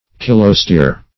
Search Result for " kilostere" : The Collaborative International Dictionary of English v.0.48: Kilostere \Kil"o*stere`\, n. [F. kilostere.